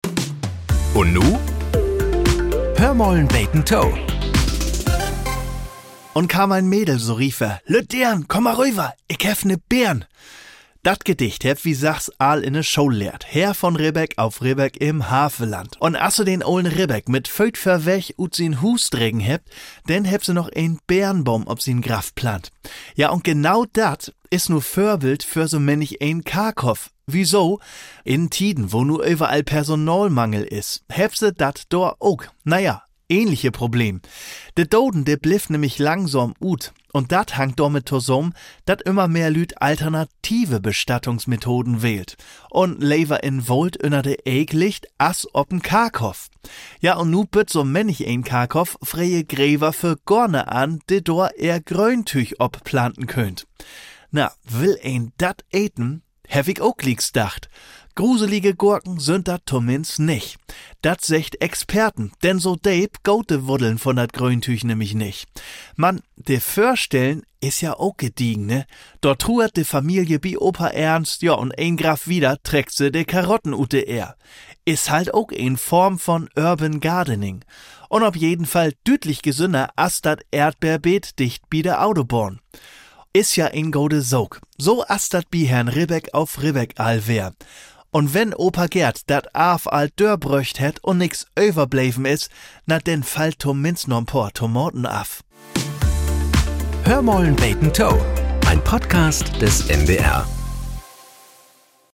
Nachrichten - 09.05.2025